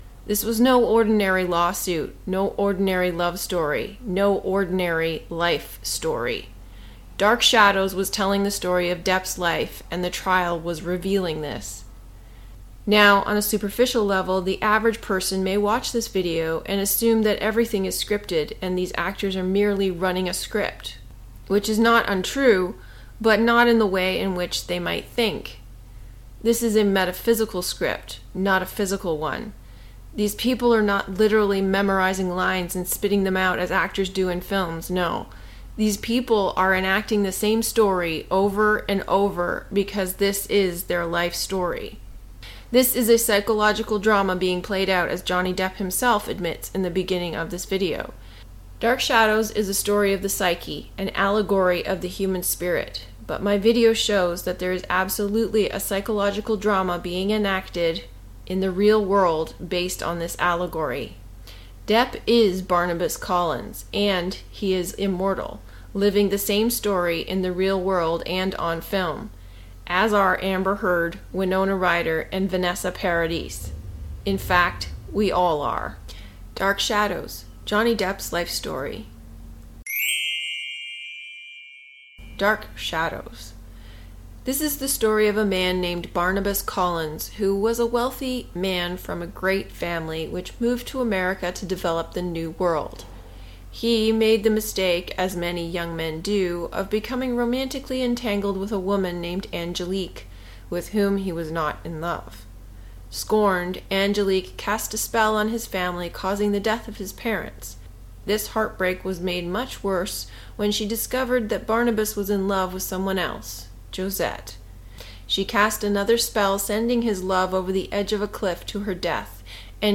• Audiobook parts 1&2